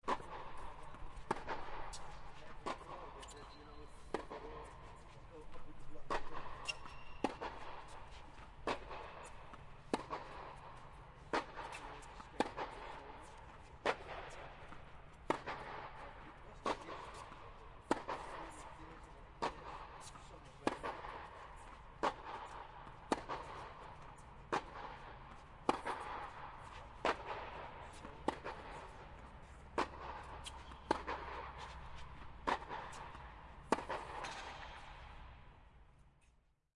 Large Ball Bouncing
描述：This is a recording of a large ball being bounced such as a volley ball. Recorder: TASCAM DR40
标签： sport racket bouncing large bounce big floor OWI ball
声道立体声